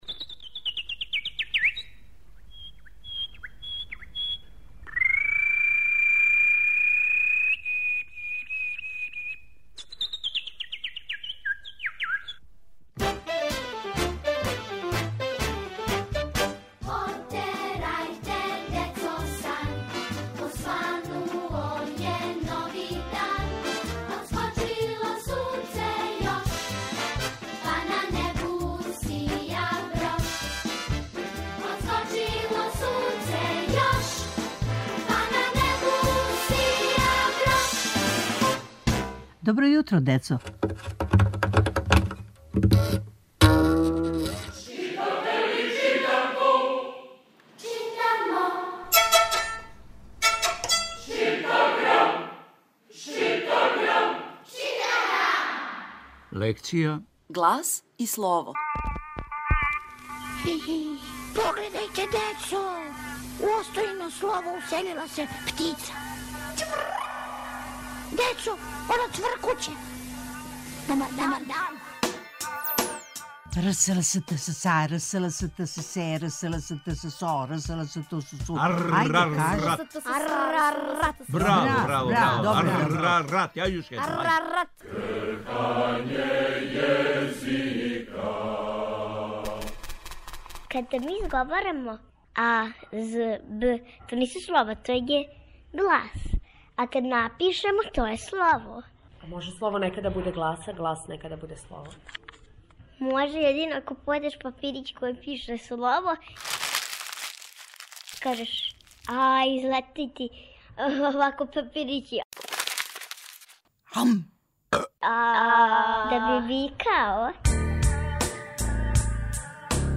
Сваког понедељка у емисији Добро јутро, децо - ЧИТАГРАМ: Читанка за слушање.